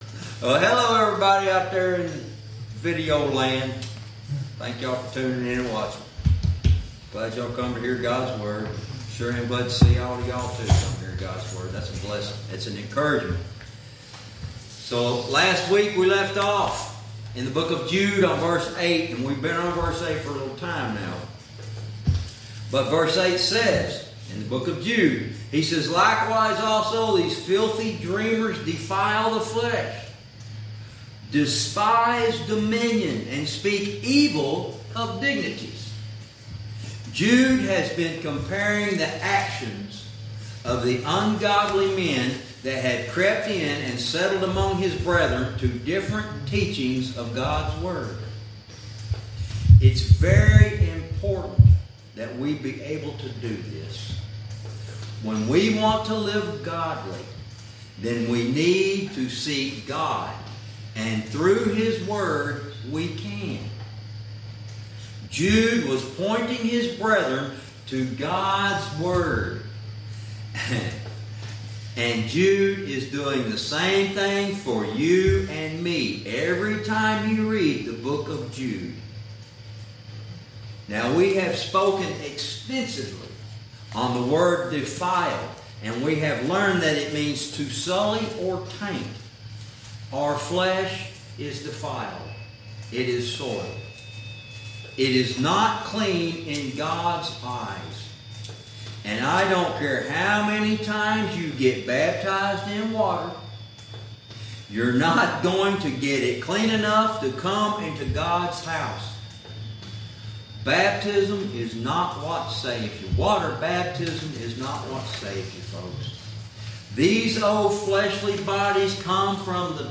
Verse by verse teaching - Lesson 27